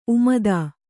♪ umadā